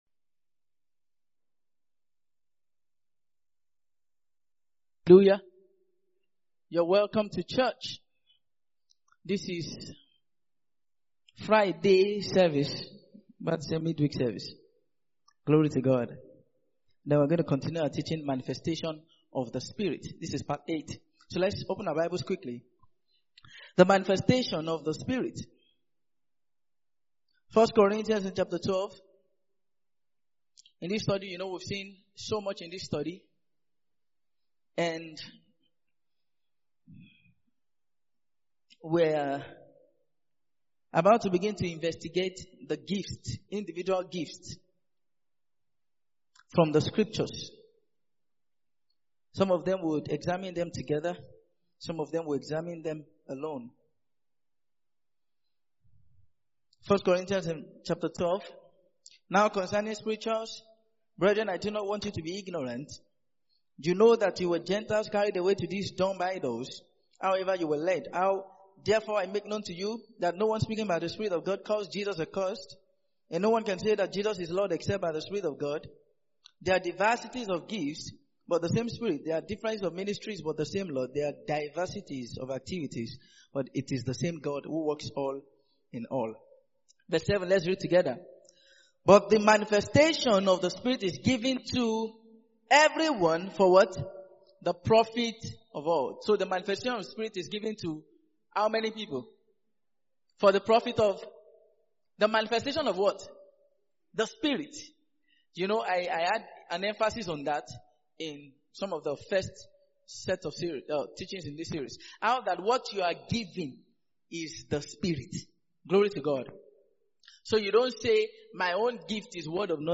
Mid-Week Sermons